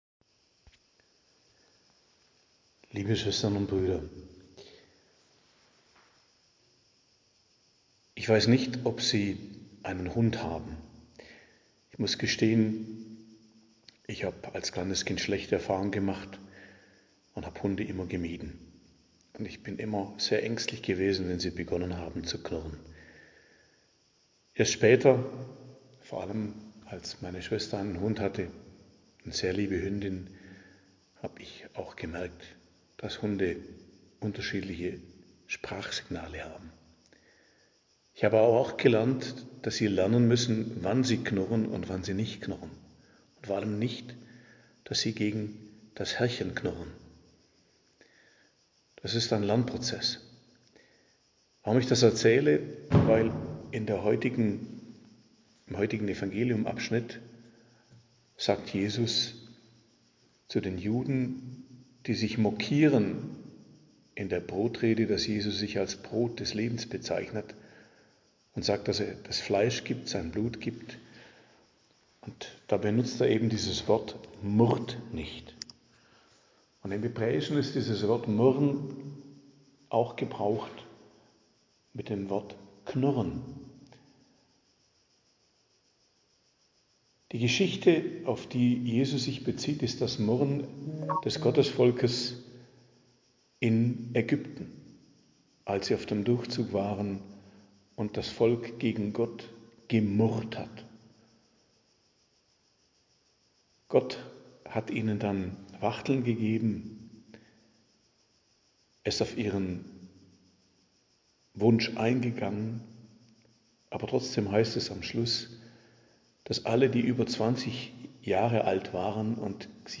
Predigt zum 19. Sonntag im Jahreskreis,11.08.2024